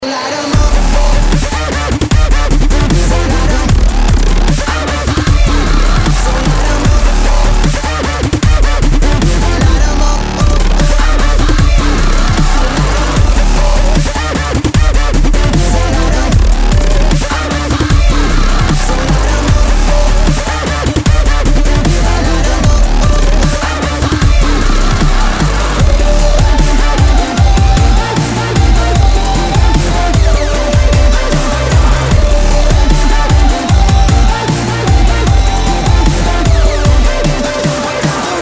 • Качество: 192, Stereo
Дабстеп-Ремикс